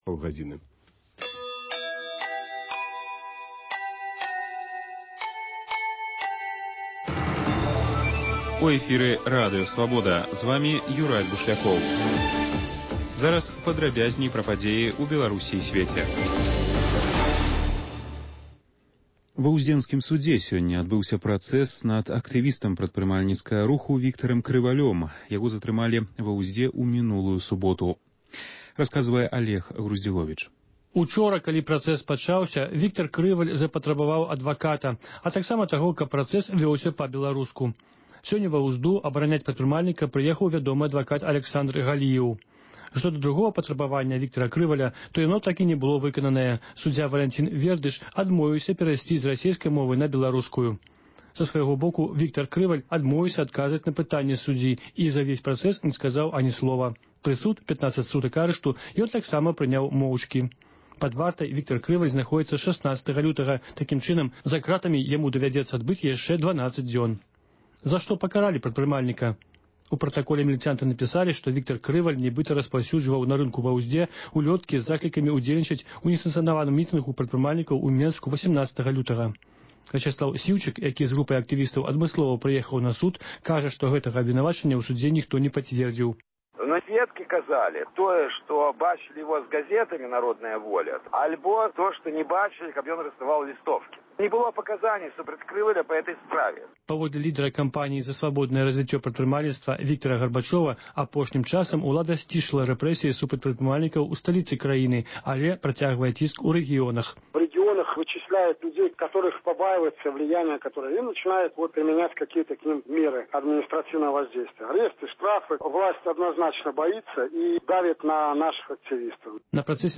Рэпартажы і паведамленьні нашых карэспандэнтаў, званкі слухачоў, апытаньні на вуліцах беларускіх гарадоў і мястэчак.